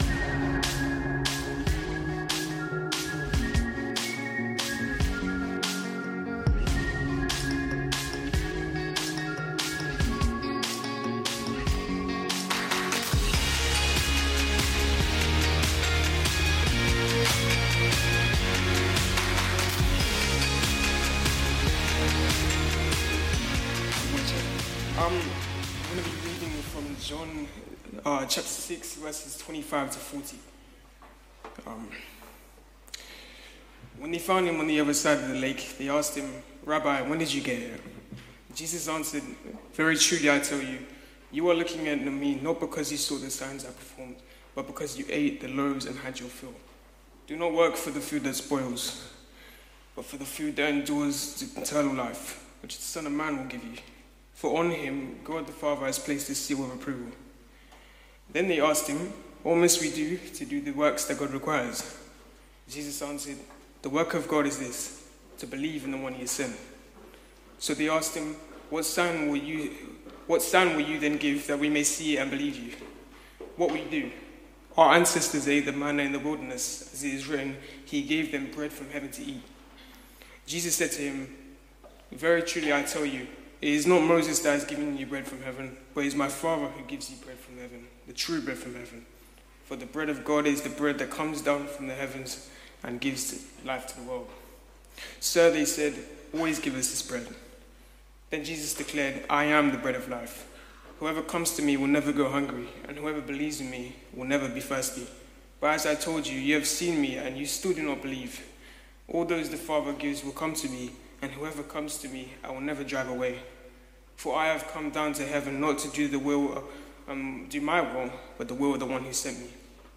Sunday Sermons - Reality Church London